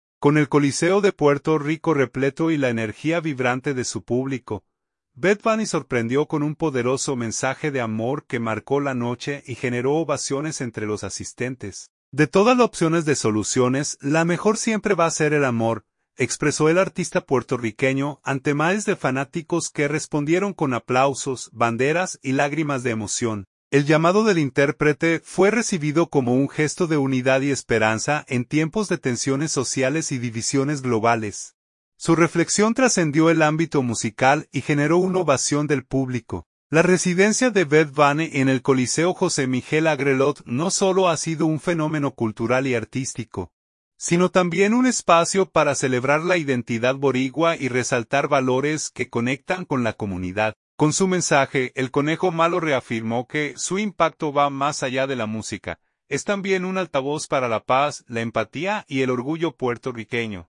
Con el Coliseo de Puerto Rico repleto y la energía vibrante de su público, Bad Bunny sorprendió con un poderoso mensaje de amor que marcó la noche y generó ovaciones entre los asistentes.
“De todas la opciones de soluciones, la mejor siempre va a ser el amor”, expresó el artista puertorriqueño,  ante miles de fanáticos que respondieron con aplausos, banderas y lágrimas de emoción.